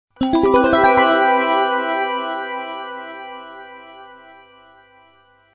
Звуковые эффекты магии, трансформации и перевоплощения идеально подойдут для монтажа видео, создания игр, подкастов и других творческих проектов.
Звук волшебства при появлении на актере новой детали например обновленной одежды